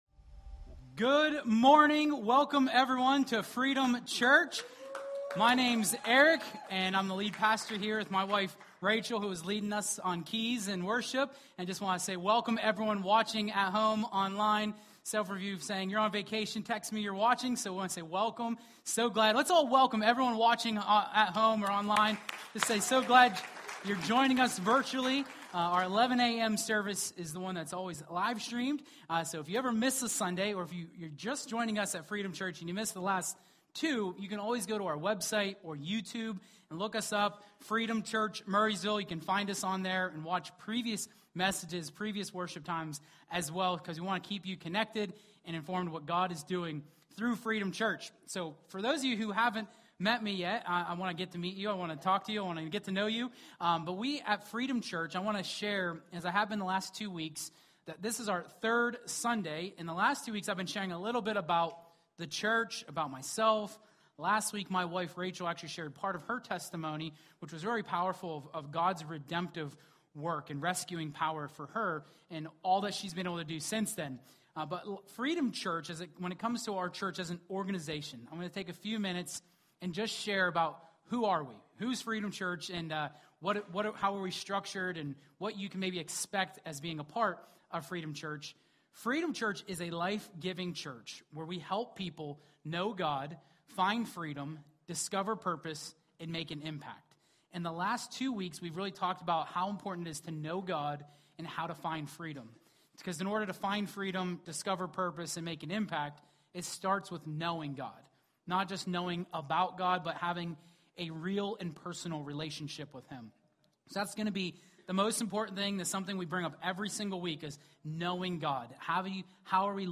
Freedom-Church-Live-3.15.26-Message.mp3